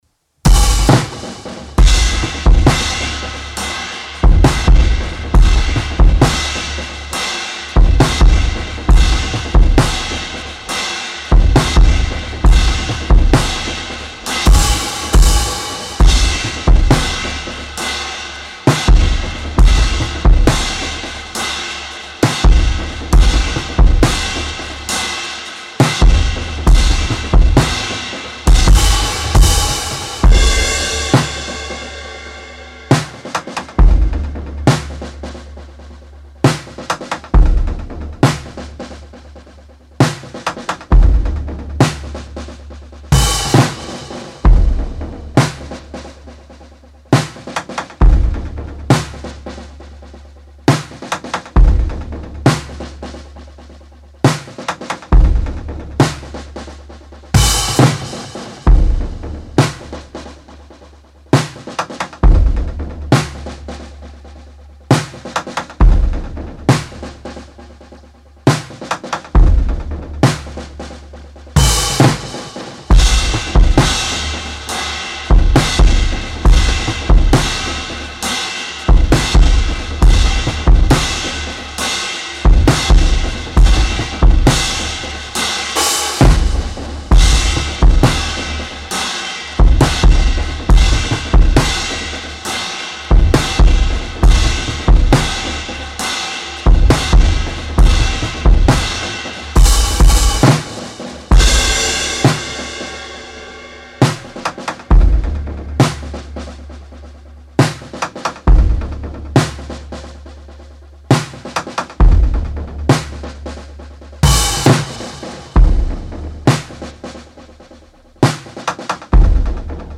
Genre:Weird, Experimental
Tempo:135 BPM (4/4)
Kit:Rogers 1983 XP8 24"
Mics:12 channels